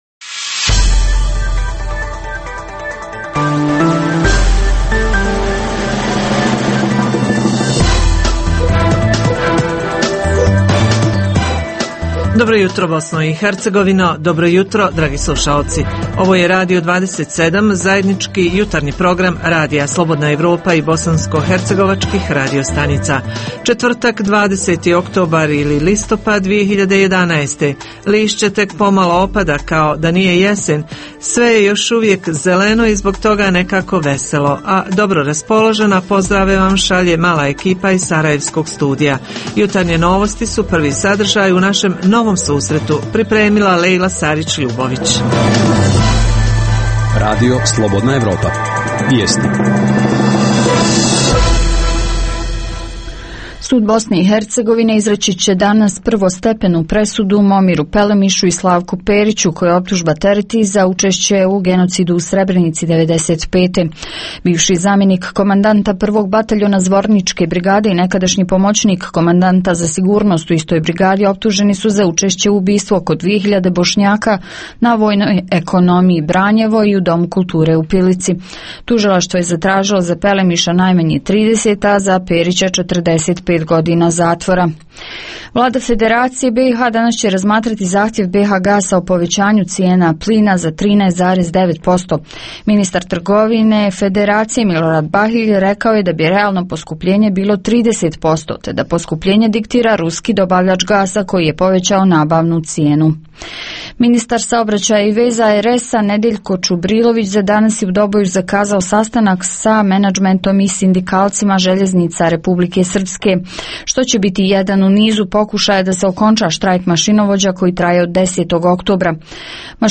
Pitamo i da li se isplati praviti ajvar, džemove, pekmeze za prodaju, odnosno za druge. Reporteri iz cijele BiH javljaju o najaktuelnijim događajima u njihovim sredinama.
Redovni sadržaji jutarnjeg programa za BiH su i vijesti i muzika.